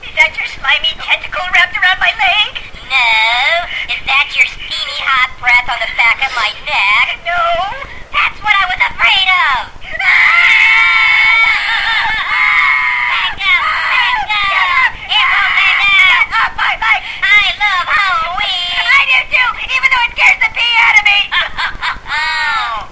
Shhh!!! What Is That? is a hoops&yoyo greeting card with sound made for halloween.
Card sound